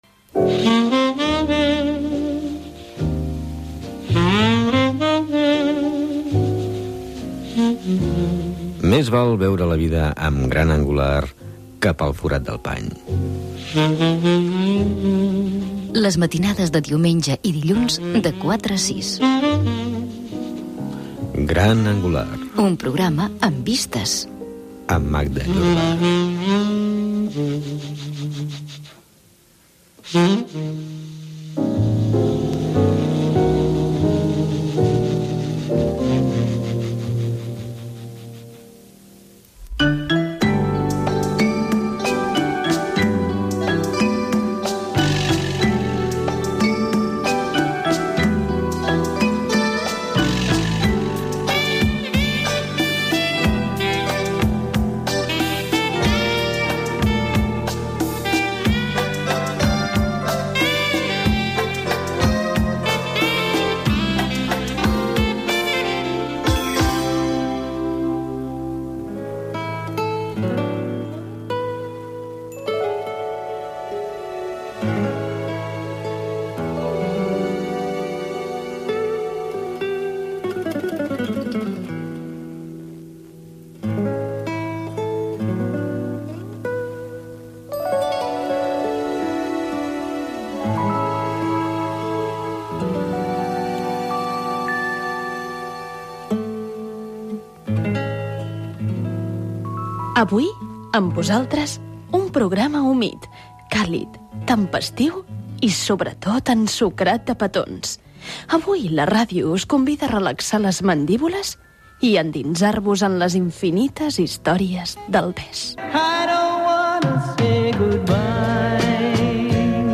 Careta del programa, indicatiu de l'emissora, presentació del programa dedicat als petons amb comentaris de les diverses menes que hi ha i fragments musicals
Entreteniment